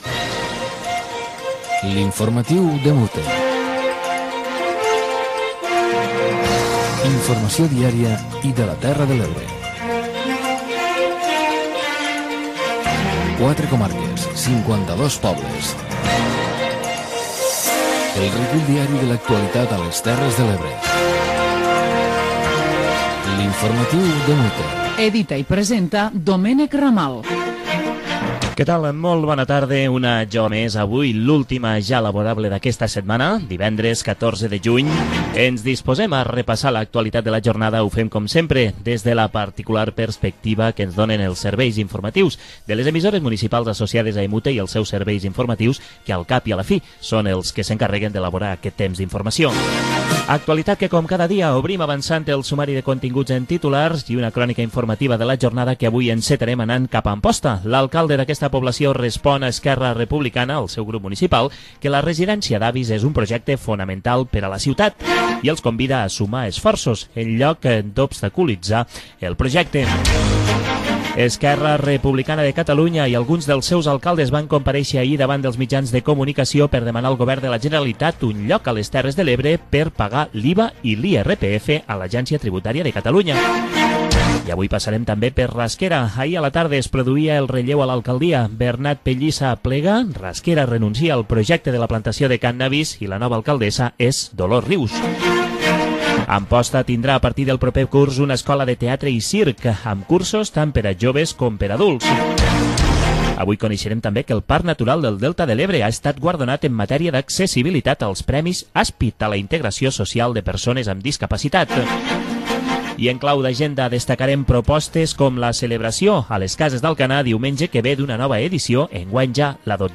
b2744a326178cd8393eb002769d0cb4626fea04e.mp3 Títol La Cala Ràdio Emissora La Cala Ràdio Titularitat Pública municipal Nom programa Informatiu EMUTE Descripció Careta del programa, sumari informatiu. Gènere radiofònic Informatiu